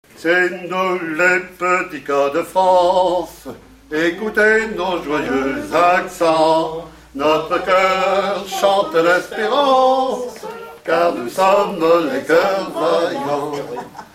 Mémoires et Patrimoines vivants - RADdO est une base de données d'archives iconographiques et sonores.
Chant des "cœurs vaillants"
Pièce musicale inédite